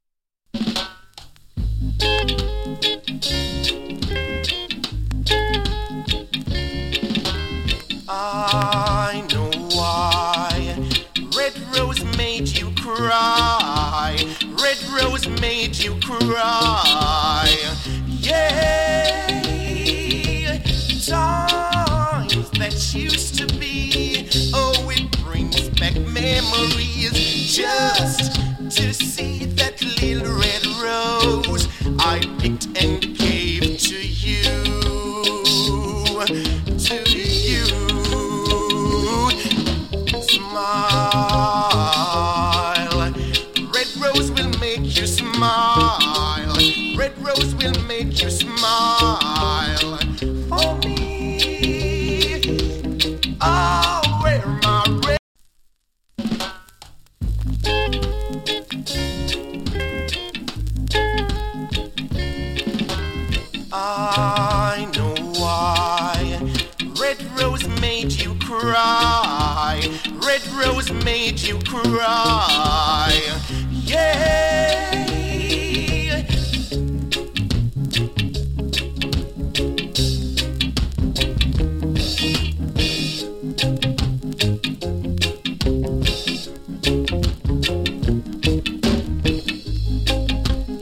7inch
チリ、ジリノイズわずかに有り。
COVER !